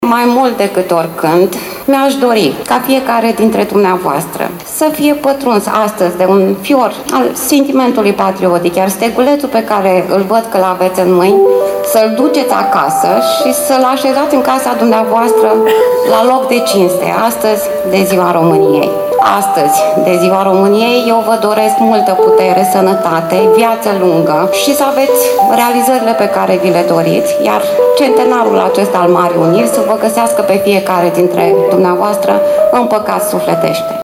Pe un ger puternic, câteva mii de suceveni au luat parte la parada militară de Ziua Națională, desfășurată pe strada Ștefan cel Mare din fața Palatului Administrativ.
Oficialități locale și județene au susținut scurte discursuri, prefectul MIRELA ADOMNICĂI făcând apel la unitate și concluzionând că România rămâne o națiune demnă și puternică.